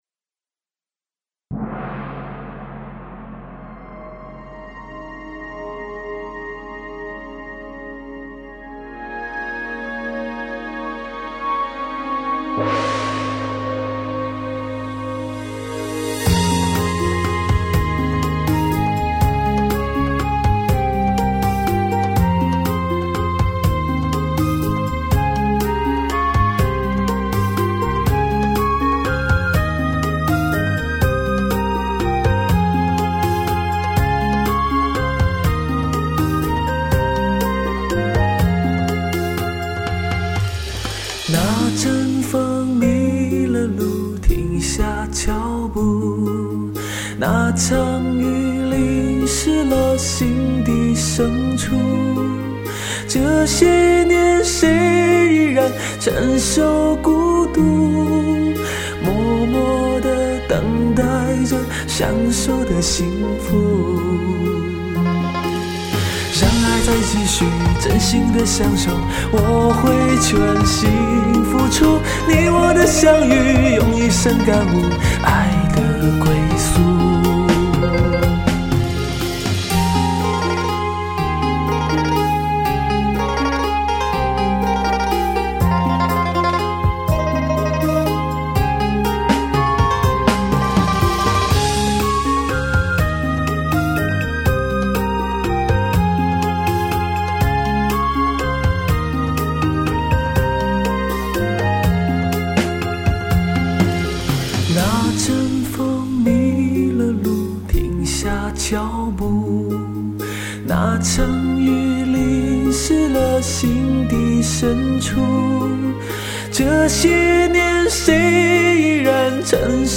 0402-吉他名曲寻找幸福.mp3